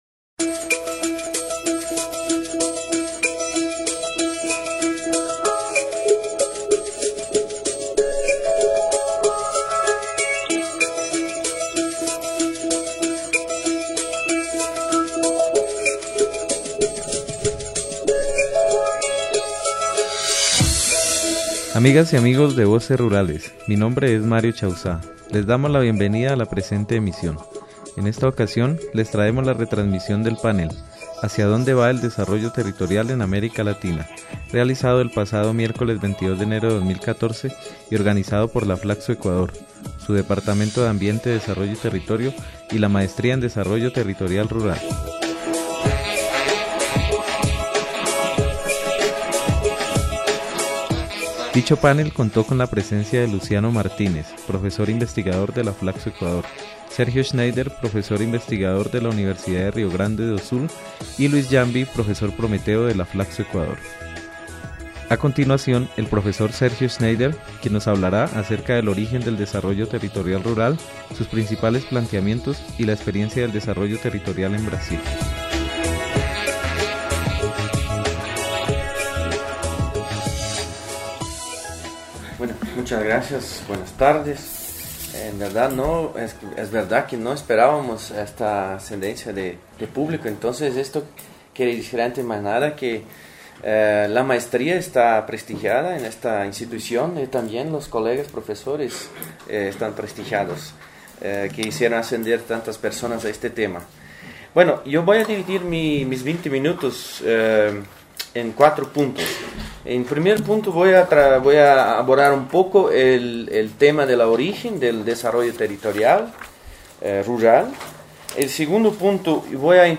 La Flacso- Ecuador, su departamento de Ambiente, Desarrollo y Territorio y la Maestría en Desarrollo Territorial Rural, organizaron el pasado miércoles 22 de enero de 2014 el panel: ¿Hacia dónde va el desarrollo territorial rural en América Latina?